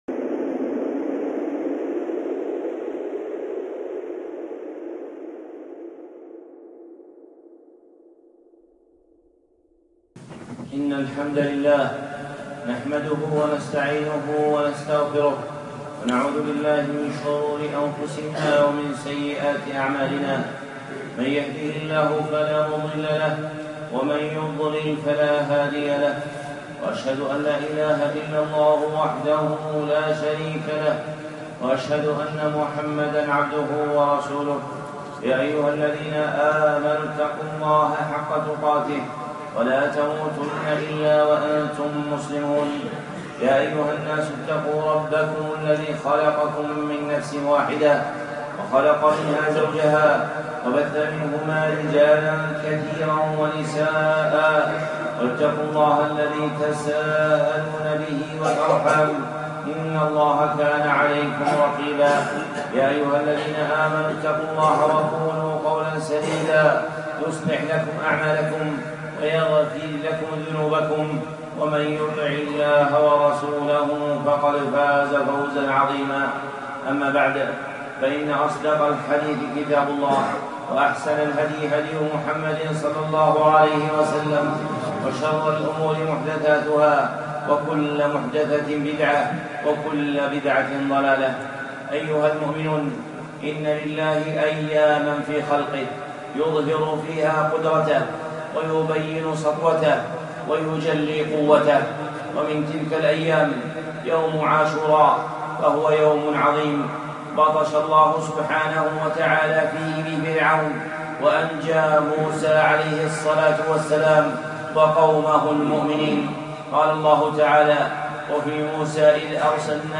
خطبة (يوم عاشوراء)